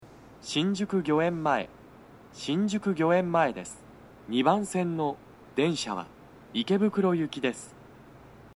mshinjukugyoemmae2toucyaku.mp3